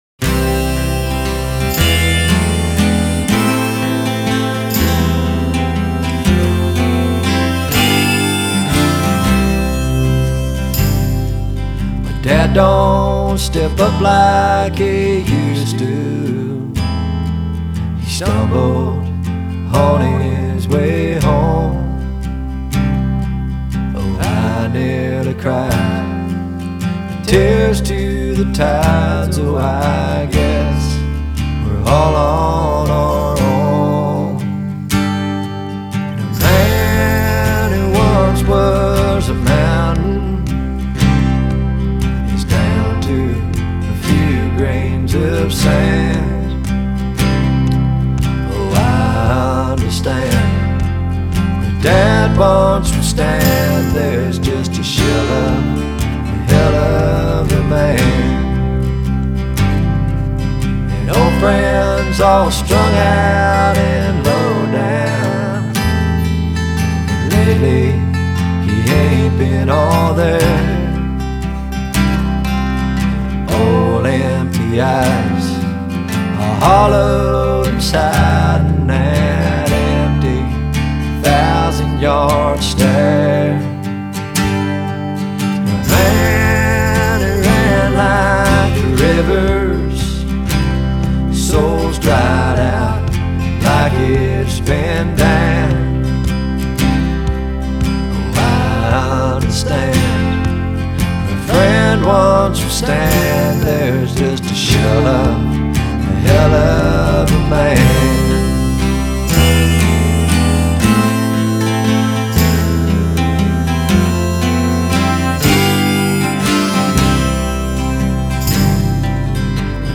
The boys just ooze the Country Outlaw feel.